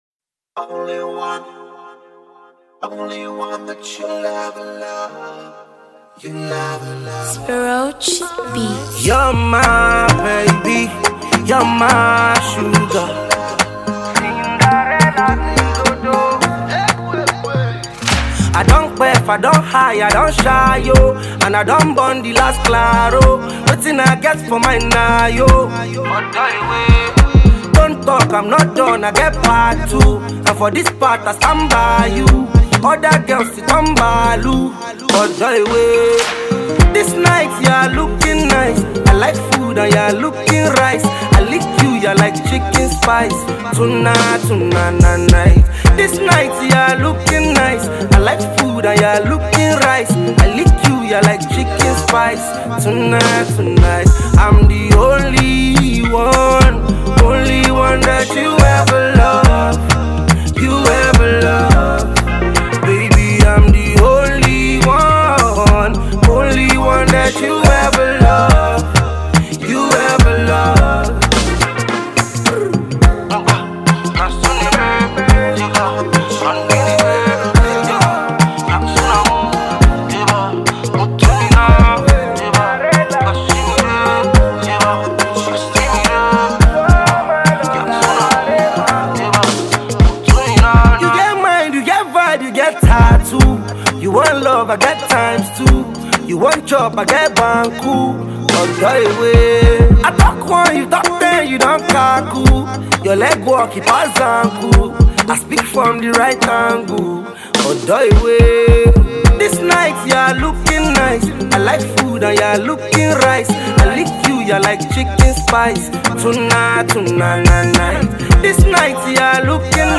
catchy single
uptempo track